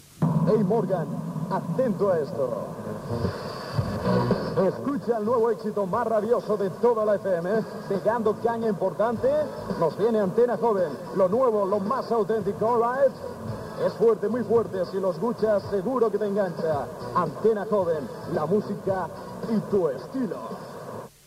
Identificació